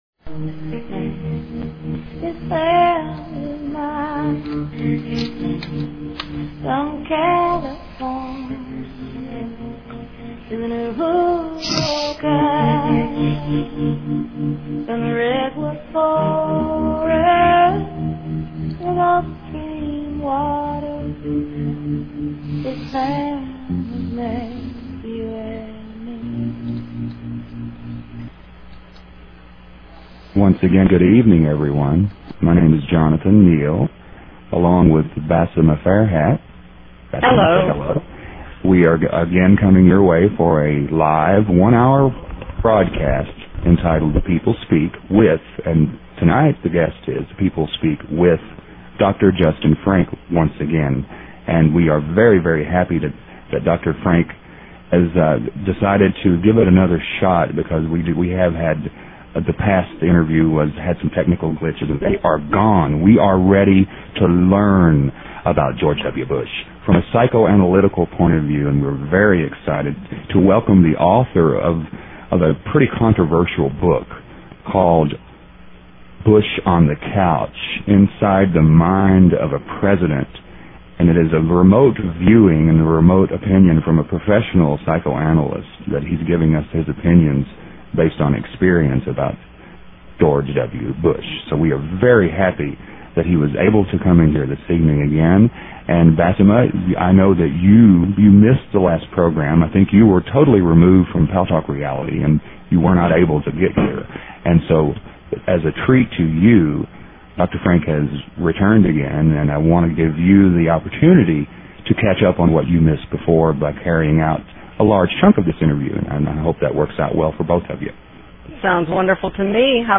The show features a guest interview from any number of realms of interest (entertainment, science, philosophy, healing, spirituality, activism, politics, literature, etc.).
The radio show name, The People Speak, is based on the idea of allowing our audience - the People - a chance to interact with the guests during the hour, and we take phone or text questions from them during the interview.